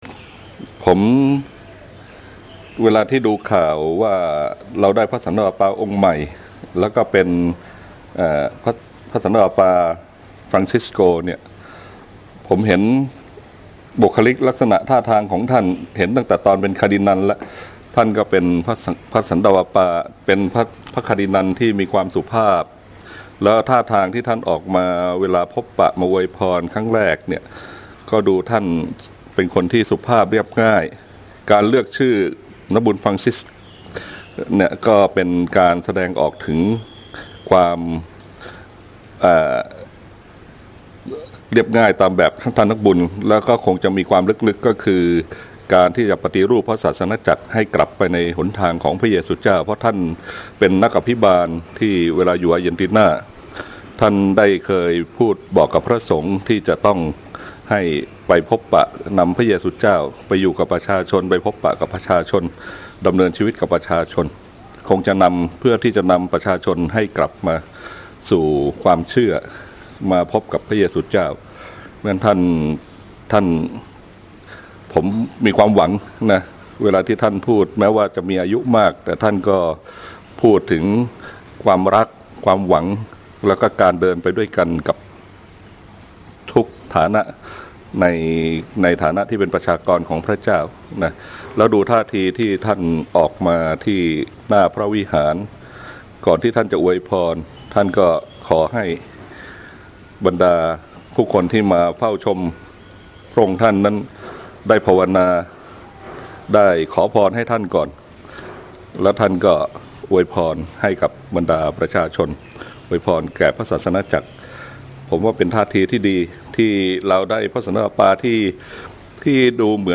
สัมภาษณ์ความรู้สึกพระสังฆราช โอกาสที่มีพระสันตะปาปา พระองค์ใหม่